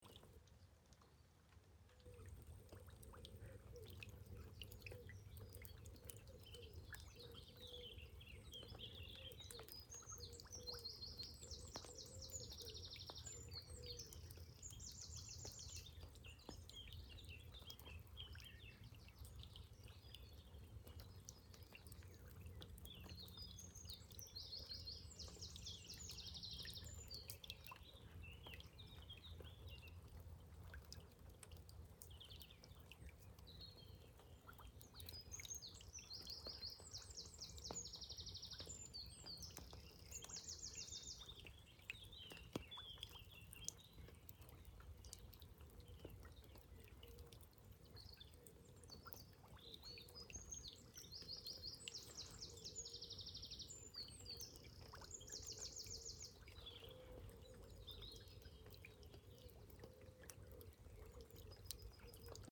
Звук дождя скачать
36. Капли заканчивающегося дождя падают в реку и птички поют
kapli-dojdia-v-reku.mp3